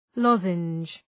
{‘lɒzəndʒ}